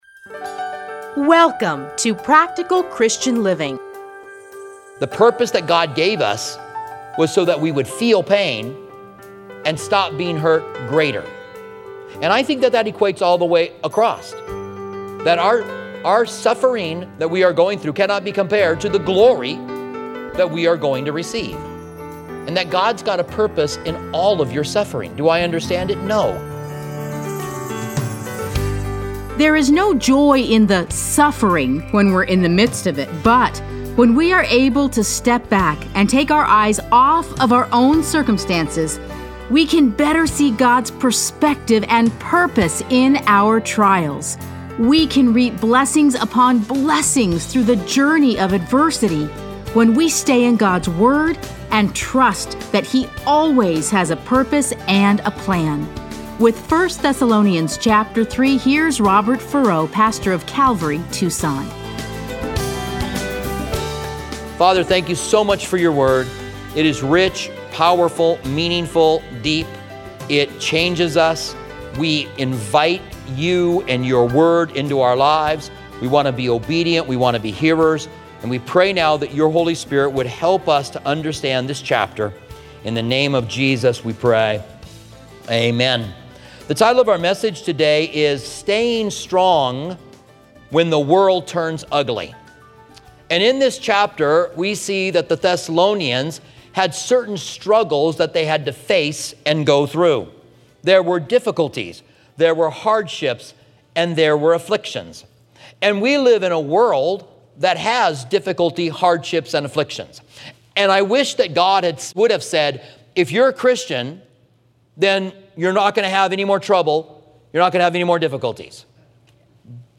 Listen to a teaching from 1 Thessalonians 3:1-13.